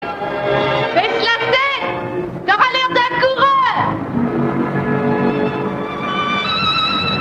Baisse la tête t’auras l’air d’un coureur [bɛs la tɛt tɔʁa lɛʁ dɛ̃ kuʁœʁ]